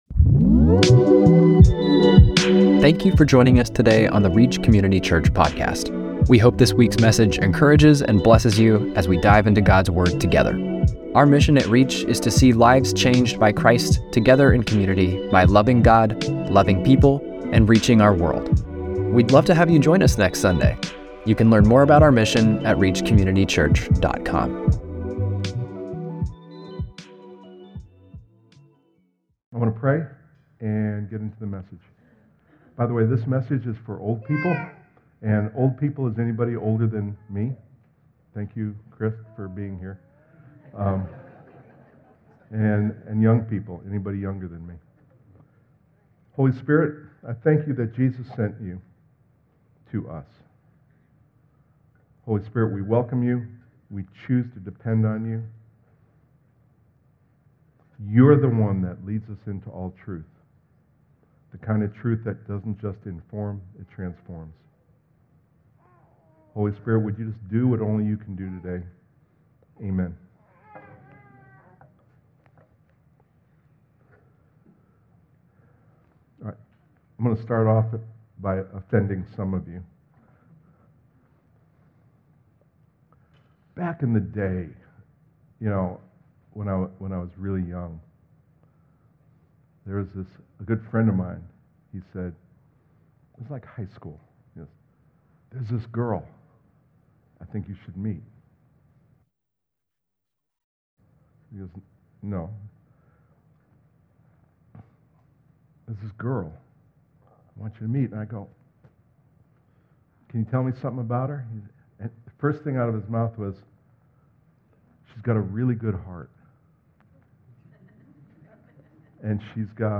2-22-26-Sermon.mp3